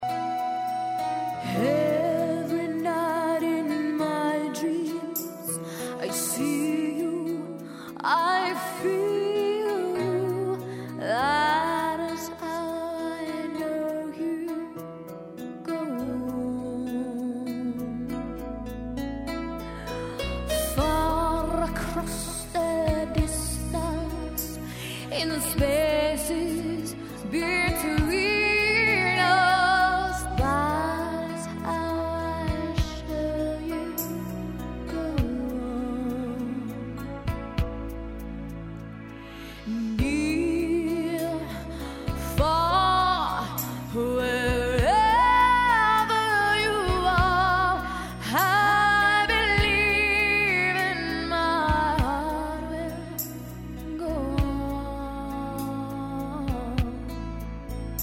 P  O  P     and    R  O  C  K      C  O  V  E  R  S